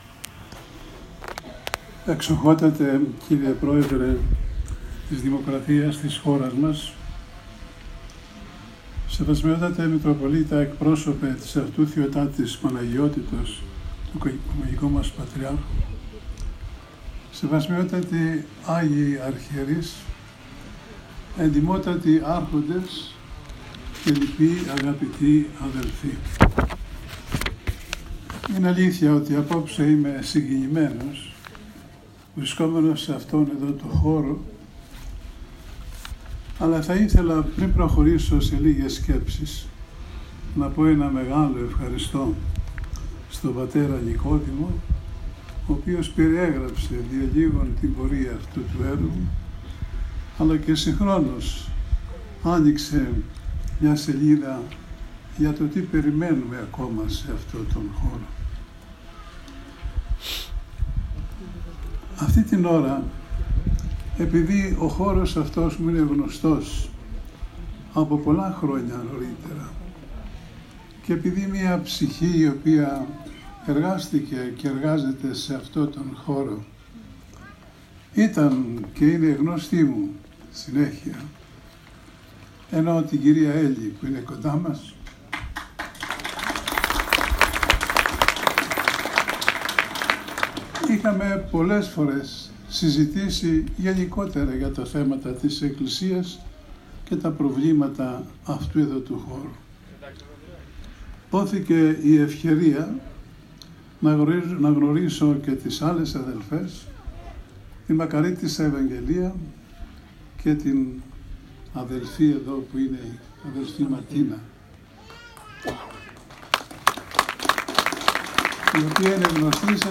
Παρουσία πλήθους κόσμου τελέσθηκαν από τον Μακαριώτατο Αρχιεπίσκοπο Αθηνών και πάσης Ελλάδος κ.κ. Ιερώνυμο Β’ τα εγκαίνια των δύο νέων Στεγών Υποστηριζόμενης διαβίωσης “Αθανάσιος Μαρτίνος 1&2” του Ιδρύματος “Μαρία Κόκκορη” της Ιεράς Αρχιεπισκοπής Αθηνών σήμερα το απόγευμα στο παράρτημα του Ιδρύματος στο Βαρνάβα Αττικής.